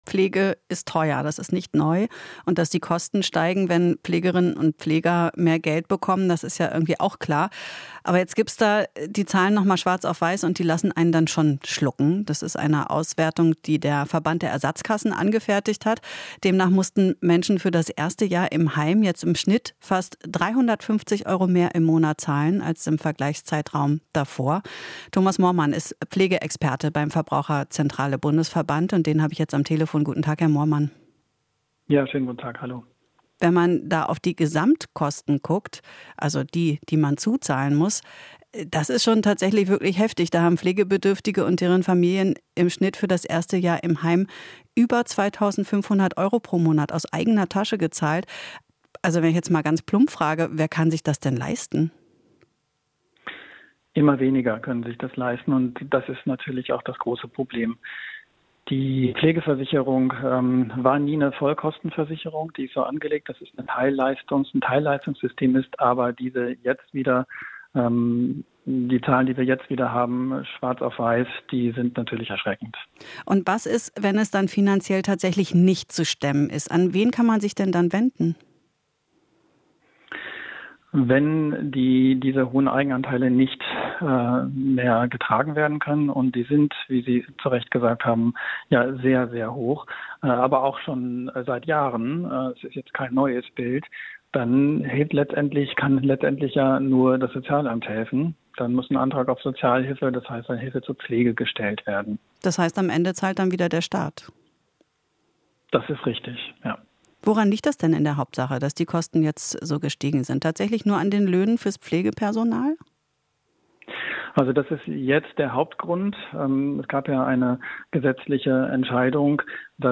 Interview - Verbraucherzentrale: Viele können sich kein Pflegeheim leisten